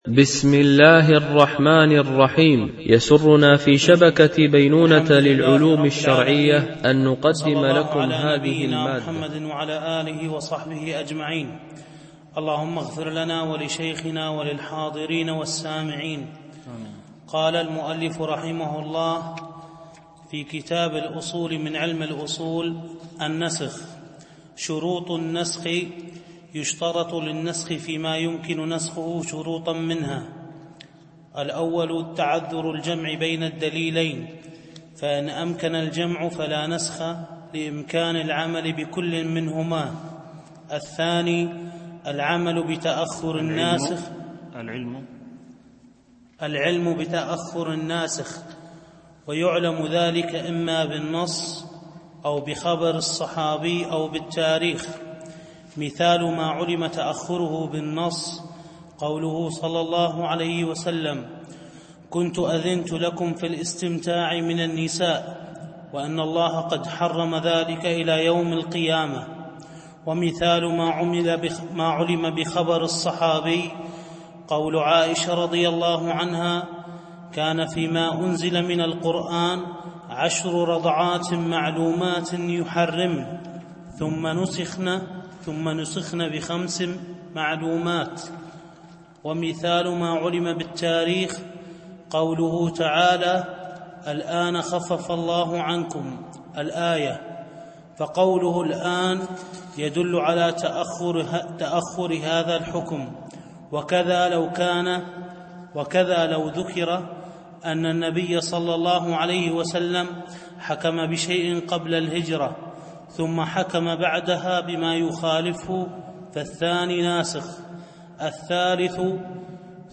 التنسيق: MP3 Mono 22kHz 32Kbps (CBR)